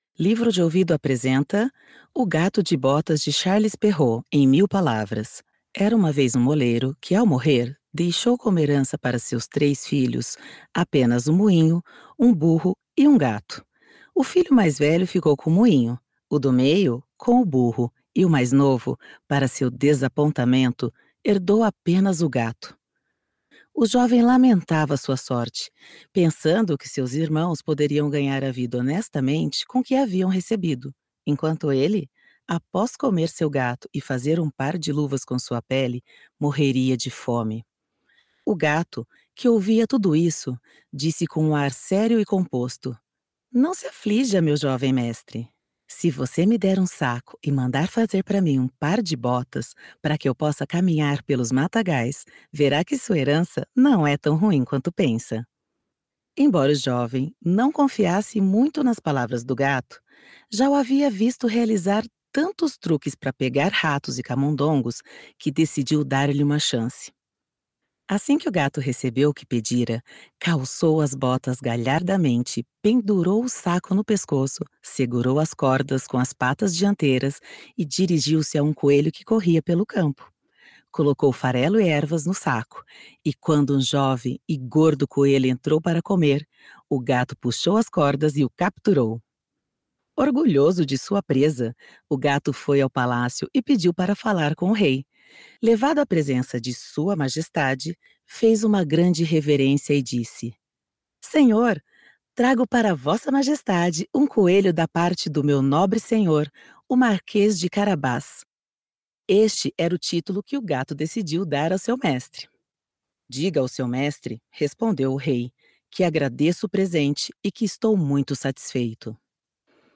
Audiolivros
Trabalho em estúdio próprio totalmente equipado e entrego áudio de alta qualidade, com flexibilidade para atender necessidades exclusivas da sua marca, negócio ou projeto. Minha voz é versátil, polida e neutra, com interpretações personalizadas para seus objetivos.
Configuração de estúdio: plataforma: Reaper, ambiente de estúdio profissional, microfone: AKG P220, interface: Focusrite-Scarlett Solo 3ª geração, monitoração de áudio: AKG K52.
Contralto